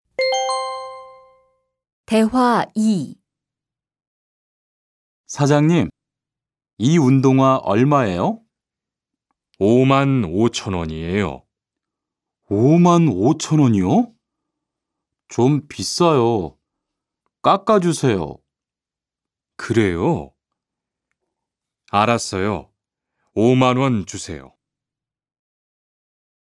Berikut adalah percakapan di toko sepatu. Rihan membeli sepatu olahraga dengan harga berapa?